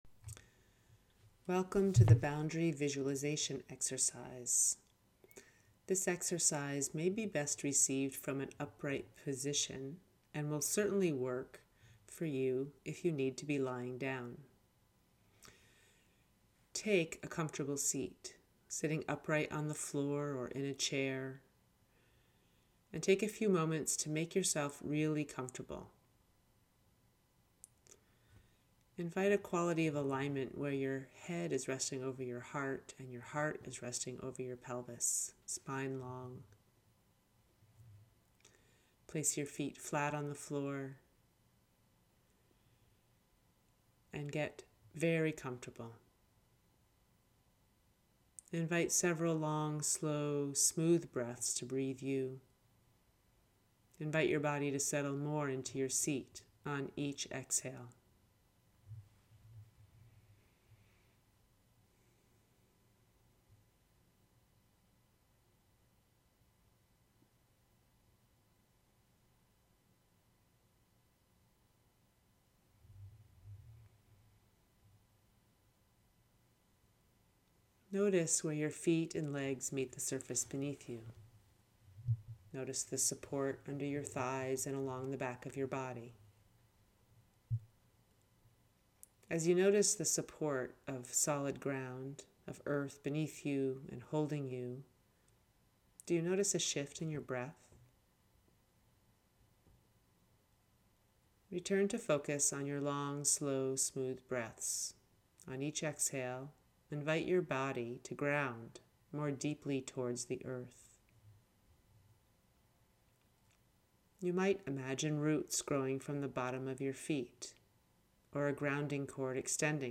Guided Imagery: Boundary Visualization
Click the button below to download your Boundary Visualization guided audio meditation.